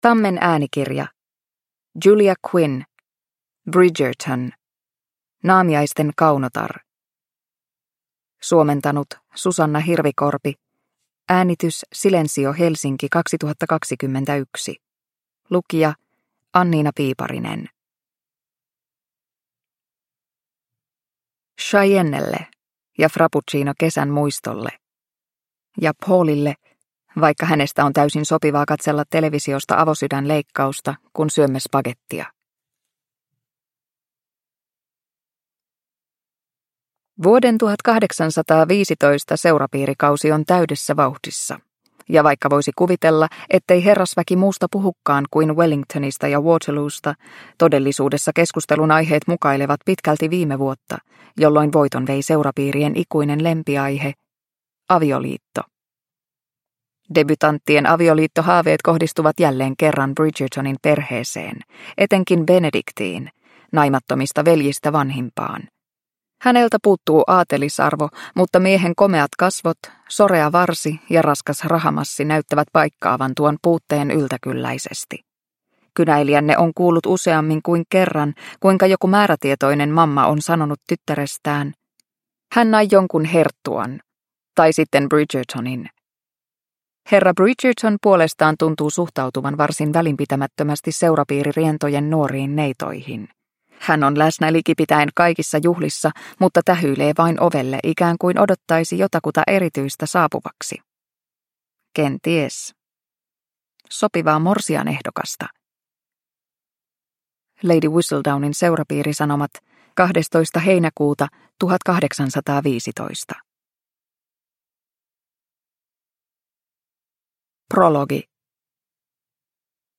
Bridgerton: Naamiaisten kaunotar – Ljudbok – Laddas ner